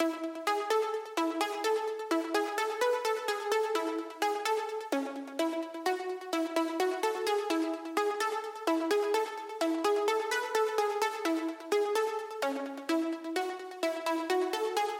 Tag: 128 bpm Electronic Loops Synth Loops 2.52 MB wav Key : Unknown